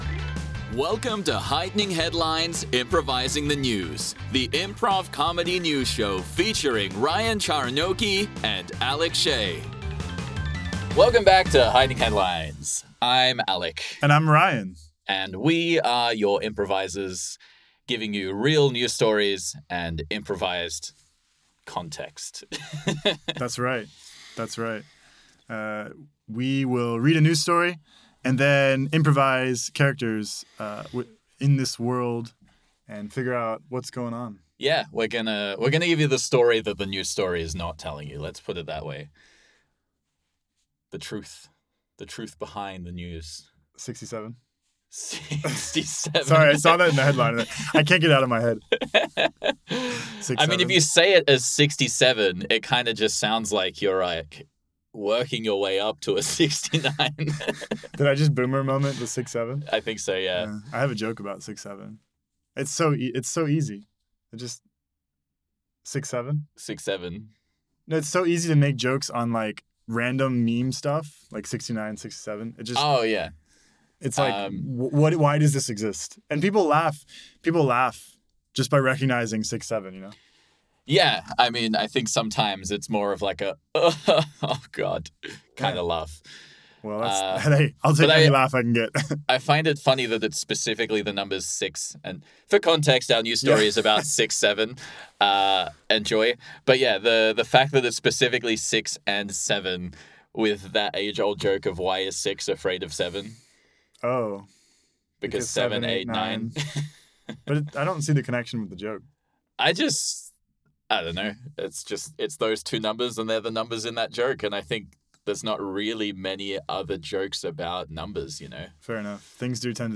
Real news, improvised comedy.